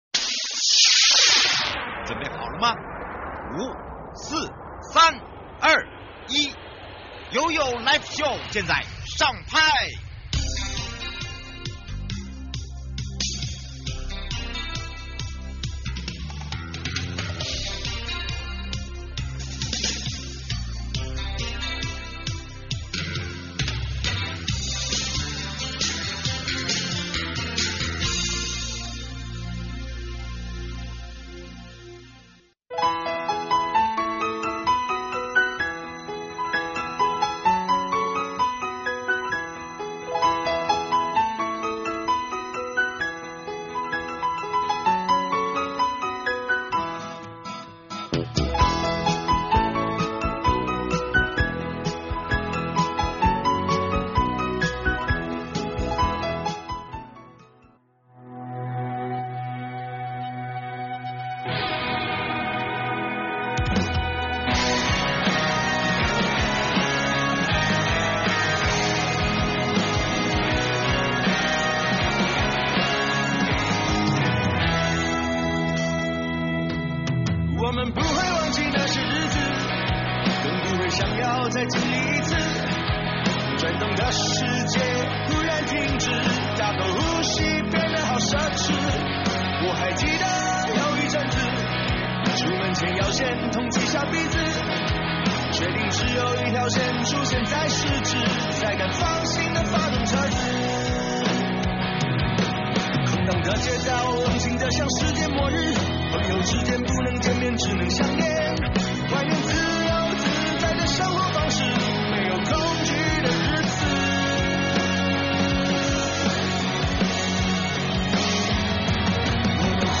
「2025草嶺古道芒花季」我來了~~ 受訪者：